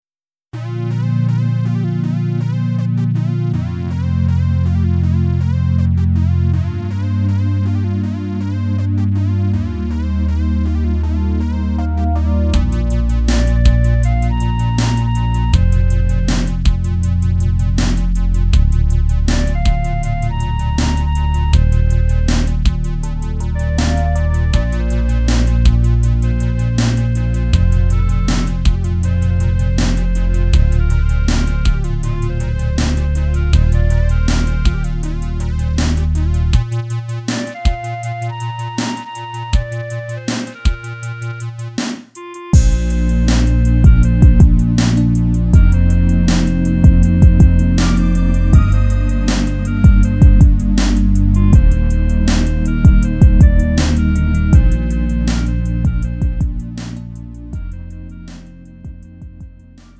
음정 -1키 4:21
장르 구분 Lite MR